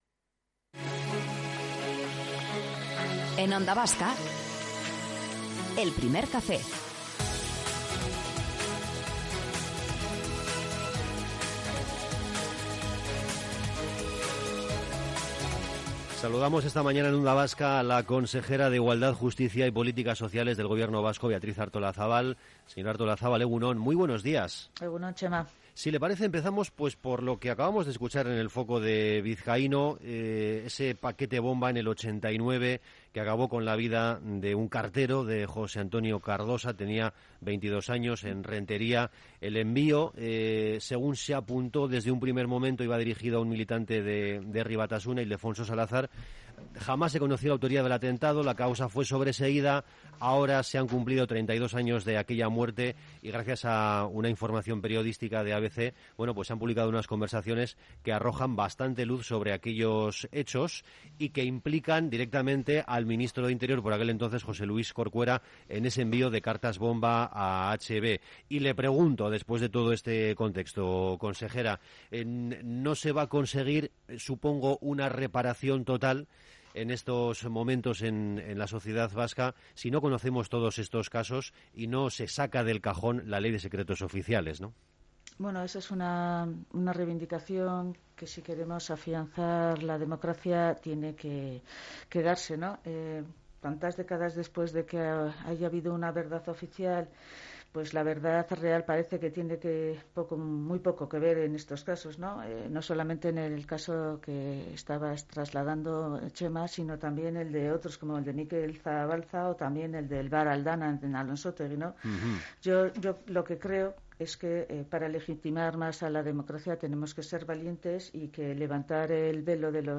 Entrevista completa con Beatriz Artolazabal - Onda Vasca
Morning show conectado a la calle y omnipresente en la red.